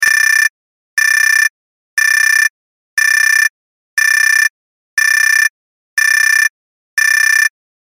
دانلود آهنگ هشدار 8 از افکت صوتی اشیاء
دانلود صدای هشدار 8 از ساعد نیوز با لینک مستقیم و کیفیت بالا
جلوه های صوتی